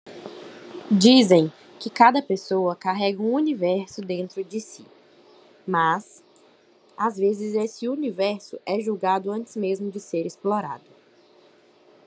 Deve-se usar o tom apelativo mas sem perder a doçura